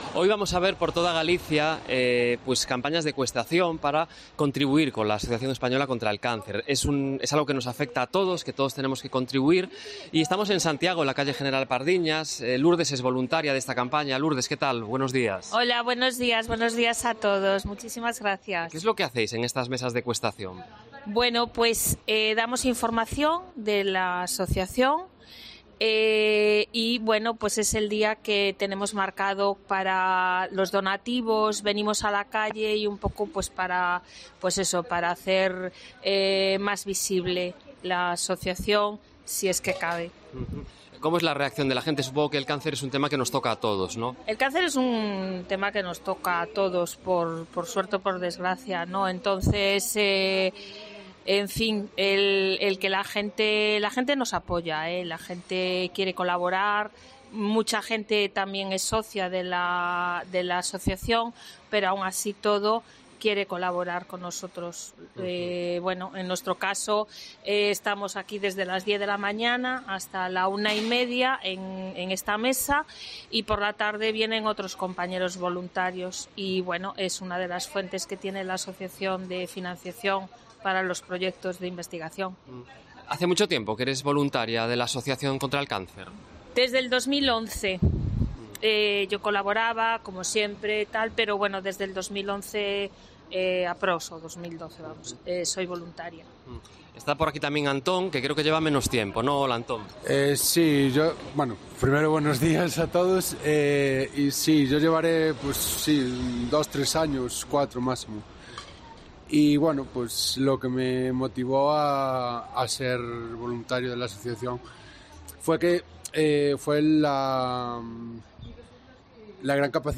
Hablamos con los voluntarios en la campaña de cuestación contra el cáncer